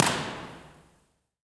Transit Center
Concrete, concrete tile, glass, plants.
Download this impulse response (right click and “save as”)
SFUTransitCenter.wav